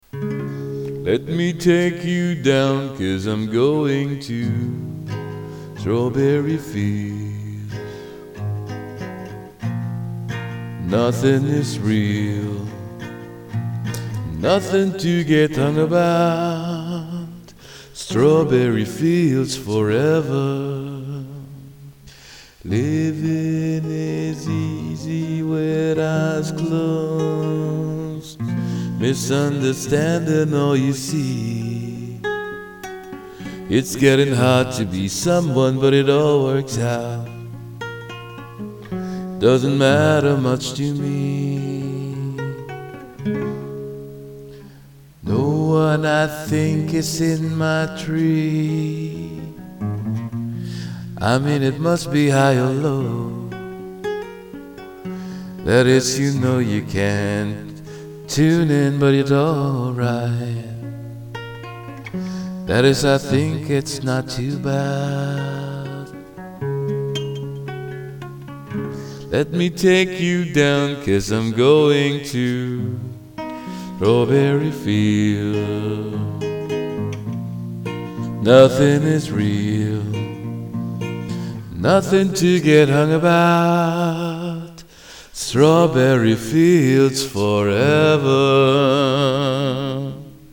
“in my life” at yung acoustic version ng